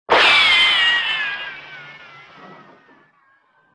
Звук рикошета пули при ударе под углом о поверхность